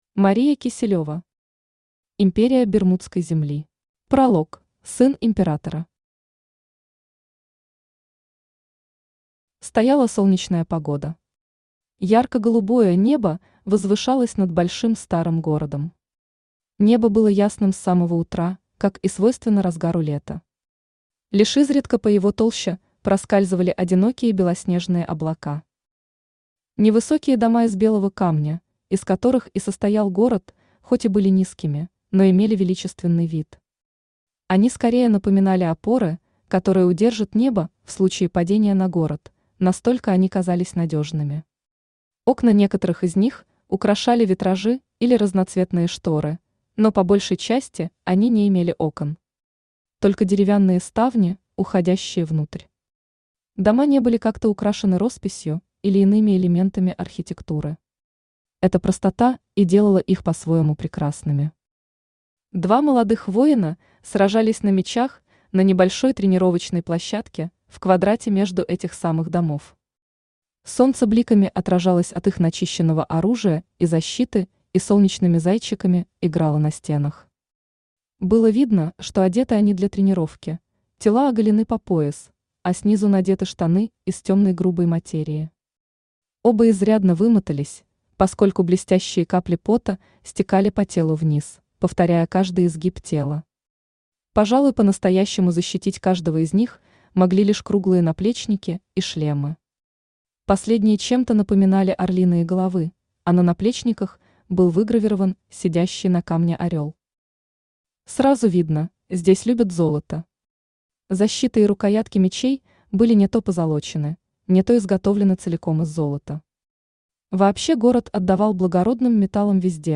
Aудиокнига Империя Бермудской земли Автор Мария Юрьевна Киселёва Читает аудиокнигу Авточтец ЛитРес.